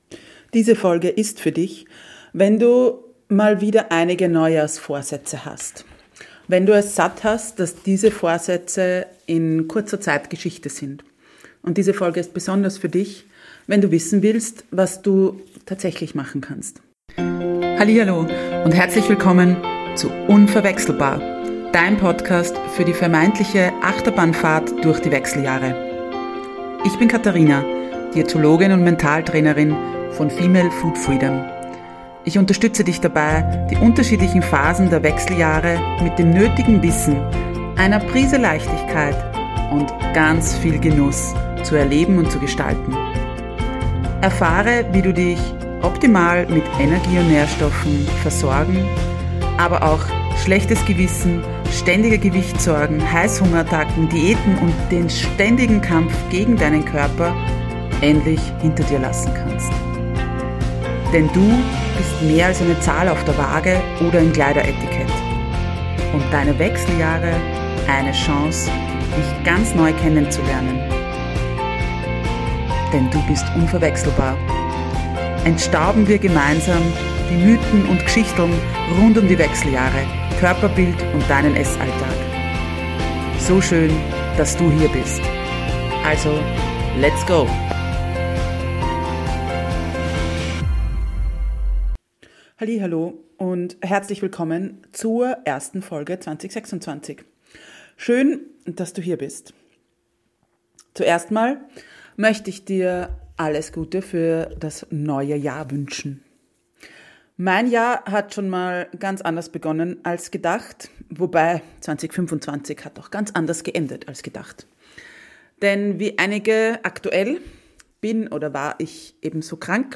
1 Meditation: Winterstille zelebrieren – für innere Ruhe & Klarheit (10 Minuten) 14:40